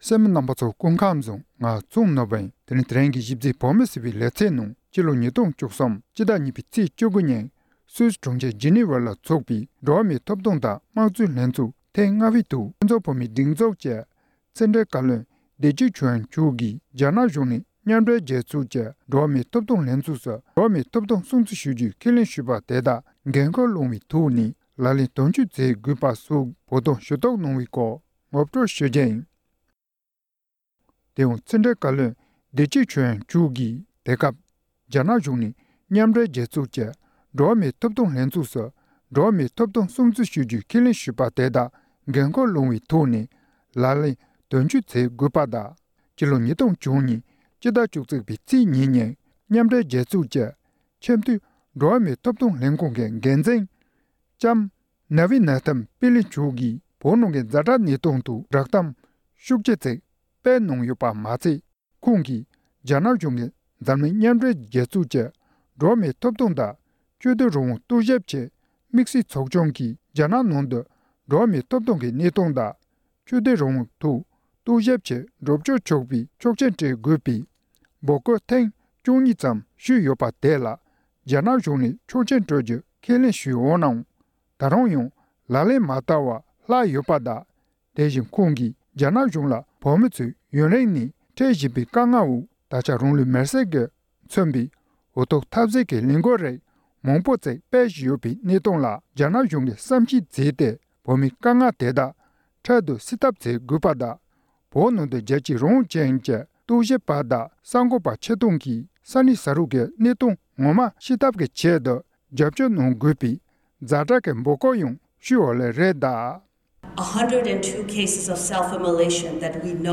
འགྲོ་བ་མིའི་ཐོབ་ཐང་དང་དམངས་གཙོའི་ལྷན་ཚོགས་ཐེངས་ལྔ་པ། ཕྱི་དྲིལ་བཀའ་བློན་མཆོག་ནས་སུད་སི་གྲོང་ཁྱེར་ཇི་ནི་བི་ཡའི་ནང་ཚོགས་པའི་འགྲོ་བ་མིའི་ཐོབ་ཐང་དང་དམངས་གཙོའི་ལྷན་ཚོགས་ཐེངས་ལྔ་པའི་ཐོག་གསུང་བཤད།
སྒྲ་ལྡན་གསར་འགྱུར། སྒྲ་ཕབ་ལེན།